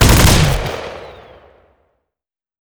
fire3.wav